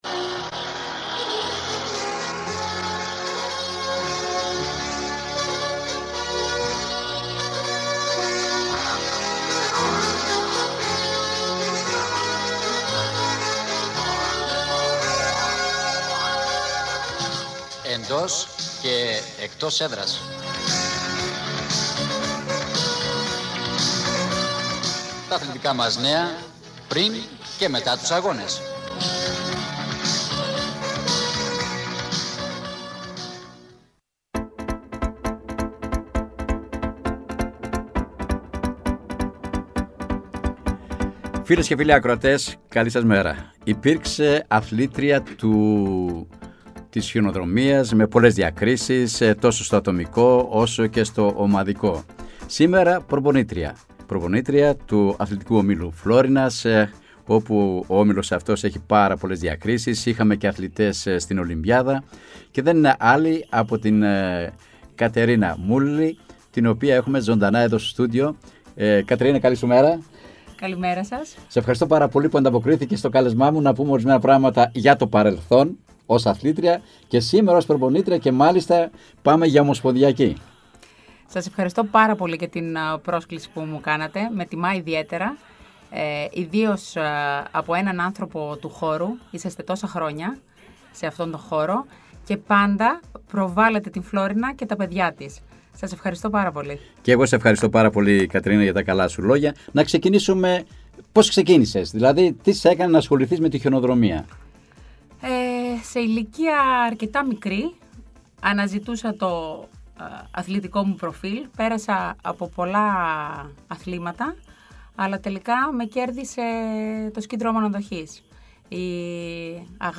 “Εντός και Εκτός Έδρας” Εβδομαδιαία αθλητική εκπομπή με συνεντεύξεις και ρεπορτάζ της επικαιρότητας, στην περιφερειακή Ενότητα Φλώρινας.